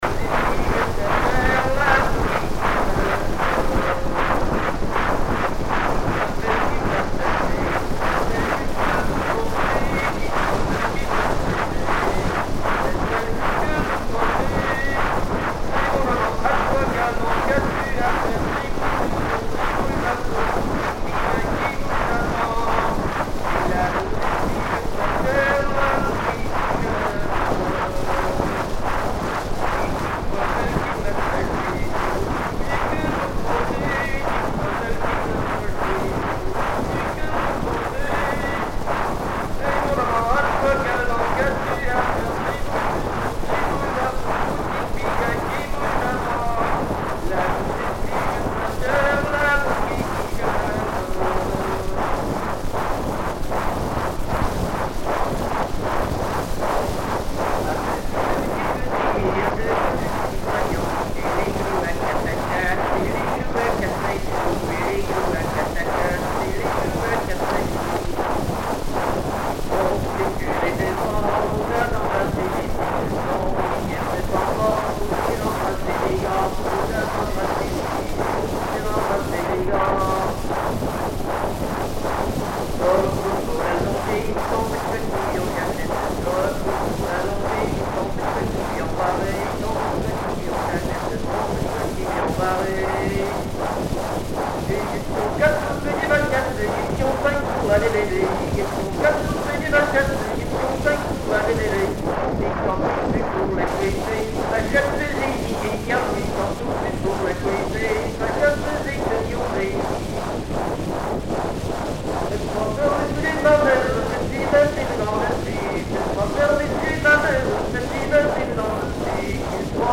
Genre énumérative
Prises de sons diverses
Pièce musicale inédite